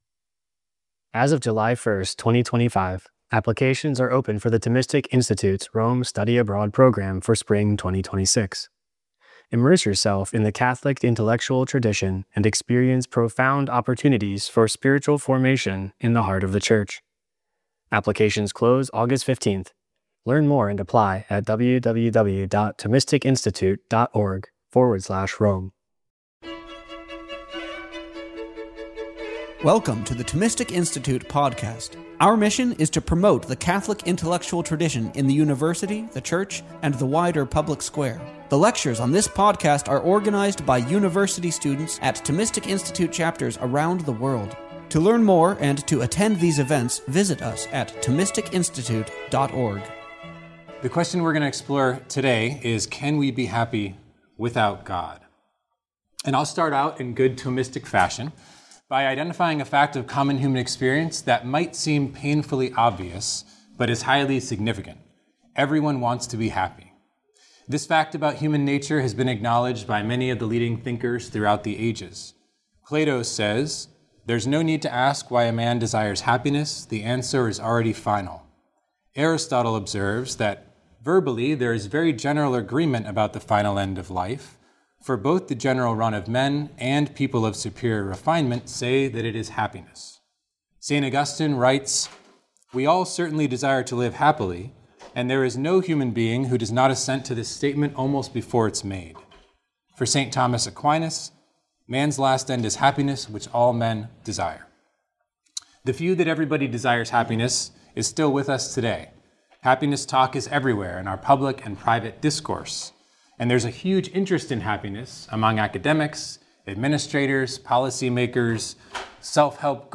This lecture was delivered to the Thomistic Institute's Harvard University Undergraduate chapter on March 21, 2019.